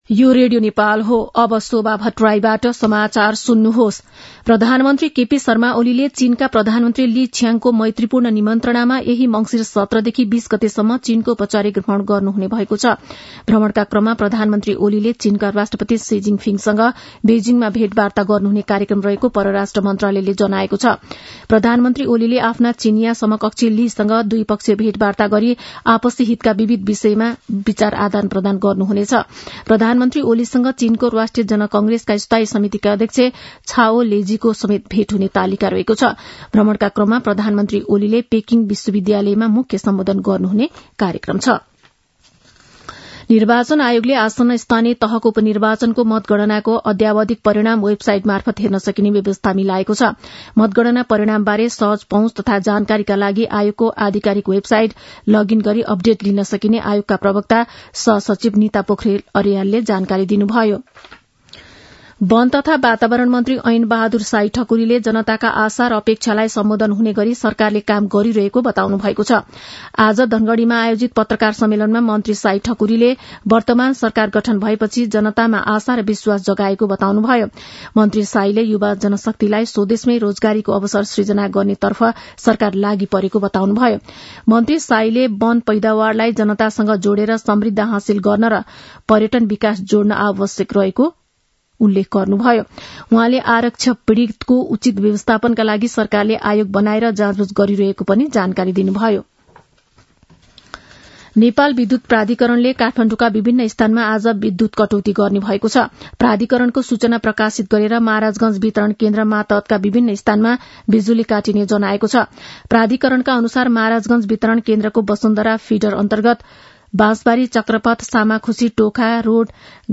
An online outlet of Nepal's national radio broadcaster
मध्यान्ह १२ बजेको नेपाली समाचार : १६ मंसिर , २०८१